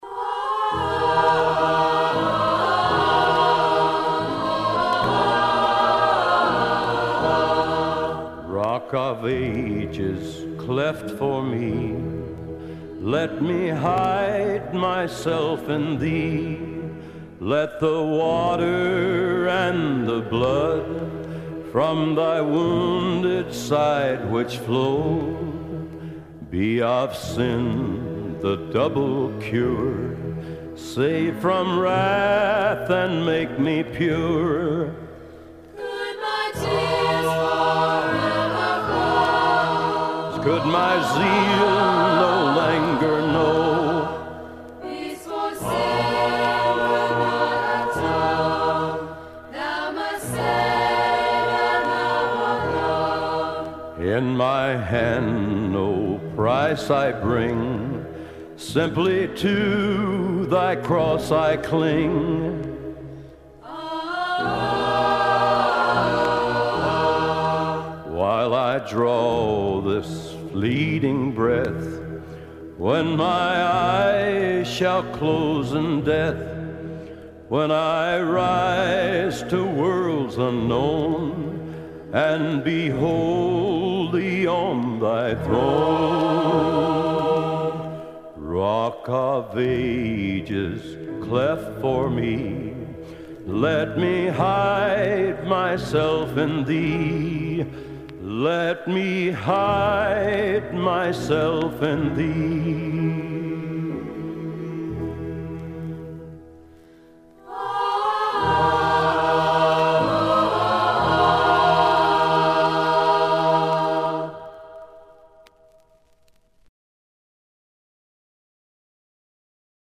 Side two was with school chorus.